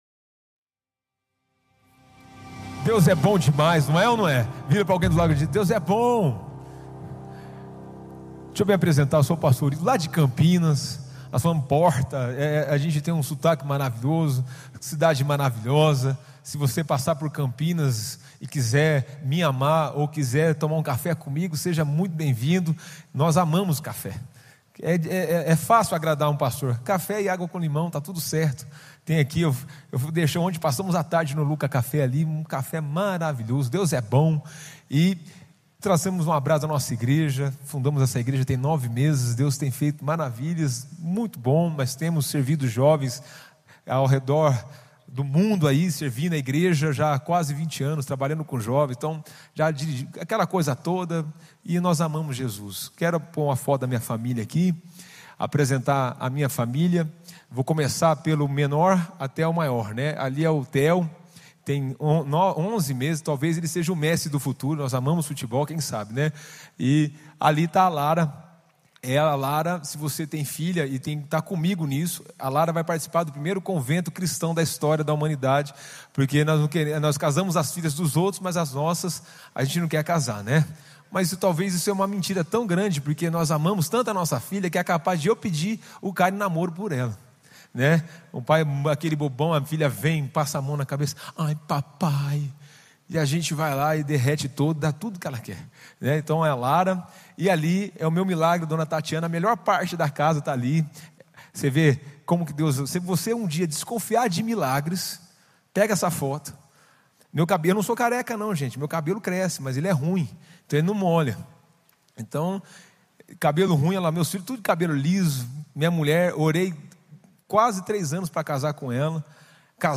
Culto ONE